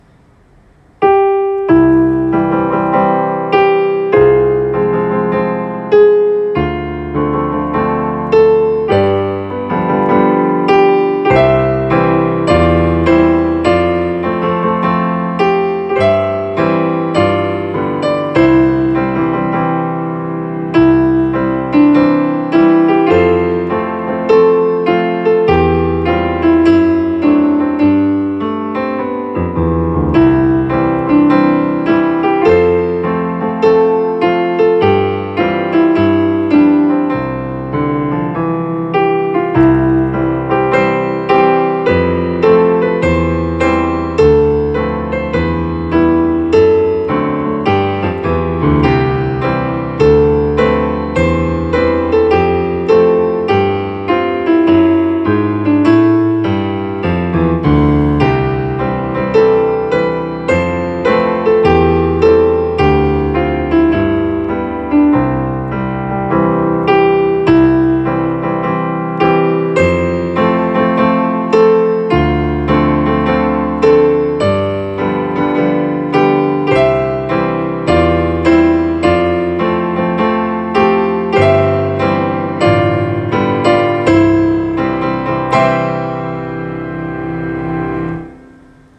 校歌鋼琴伴奏.m4a